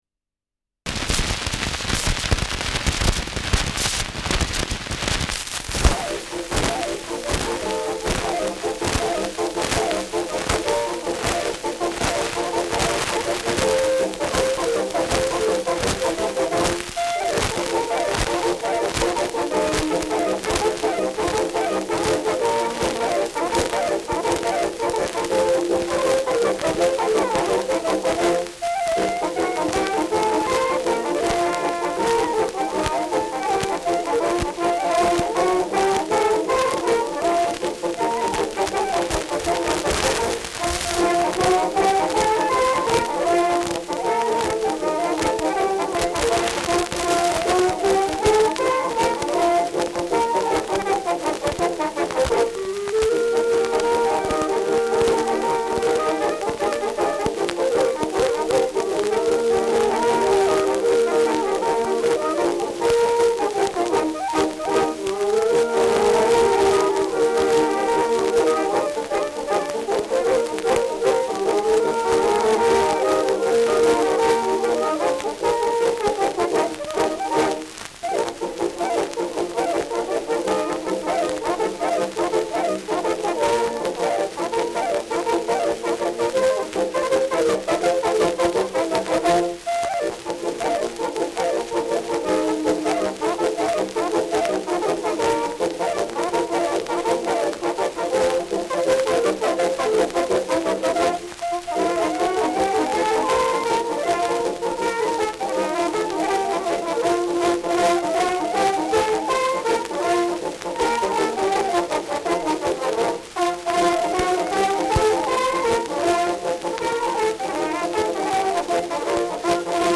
Schellackplatte
ausgeprägtes Rauschen : Knacken